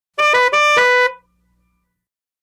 VEHICLES - HORNS OLD CAR: Two tone fanfare horn.